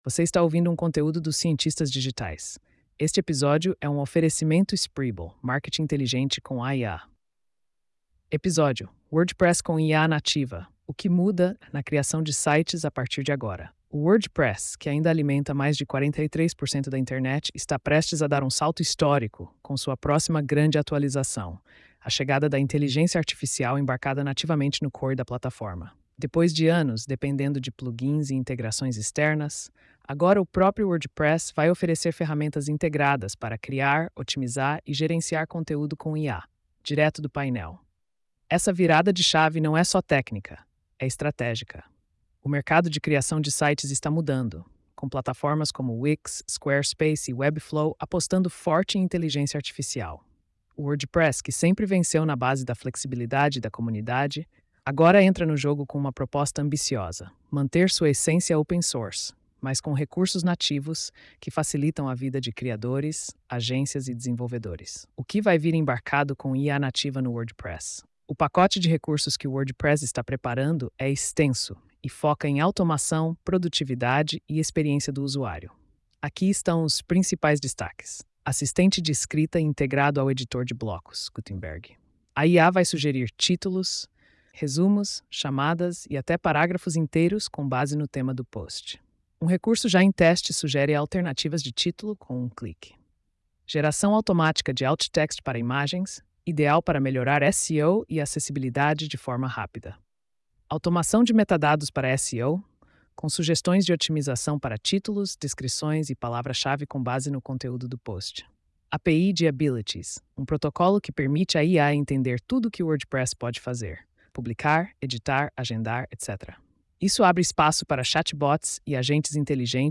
post-4581-tts.mp3